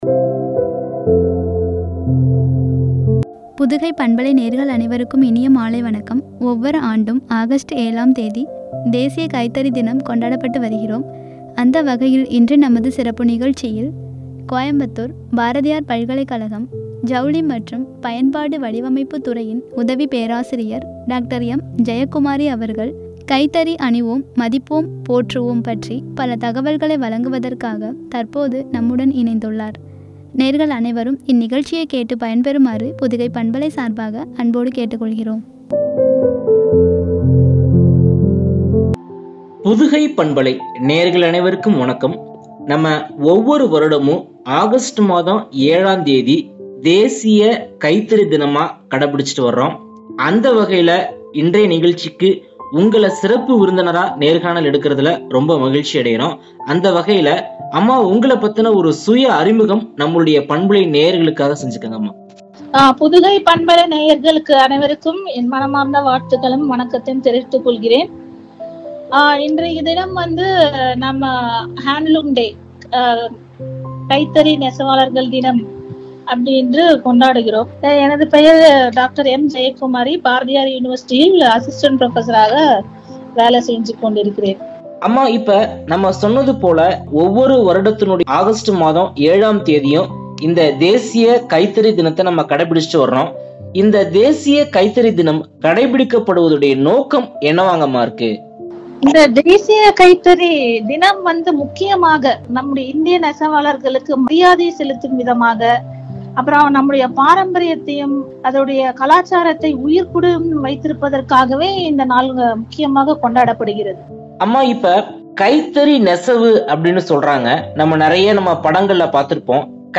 போற்றுவோம்” குறித்து வழங்கிய உரையாடல்